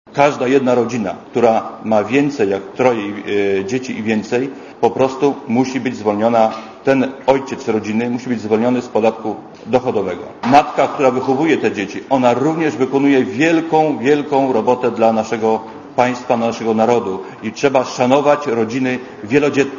Mówi Zygmunt Wrzodak